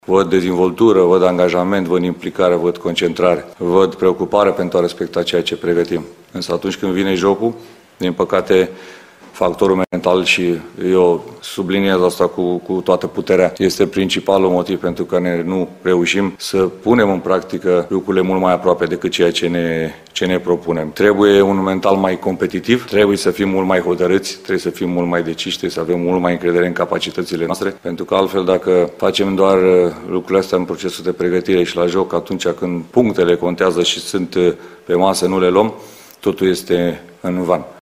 Selecţionerul Edward Iordănescu a declarat, luni, într-o conferinţă de presă, că lipsa de performanţă apasă, că lumea nu mai are răbdare şi este nemulţumită, dar consideră că echipa naţională este pe drumul cel bun.